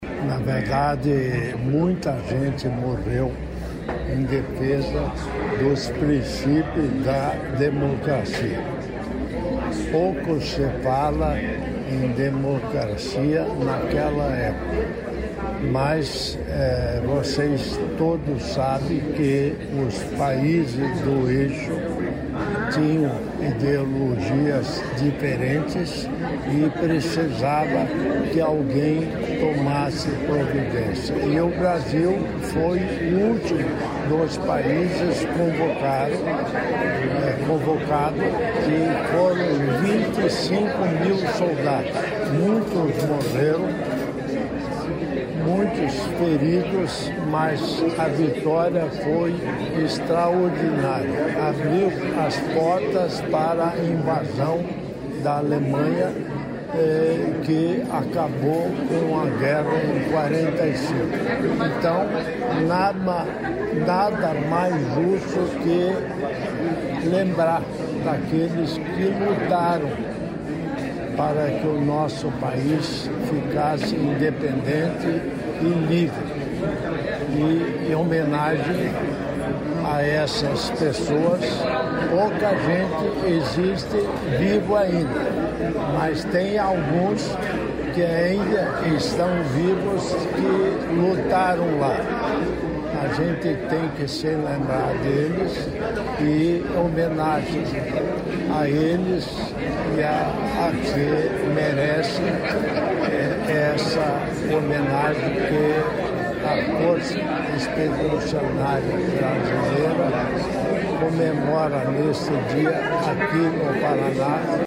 Sonora do governador em exercício, Darci Piana, sobre cerimônia em alusão à vitória emblemática da FEB na II Guerra Mundial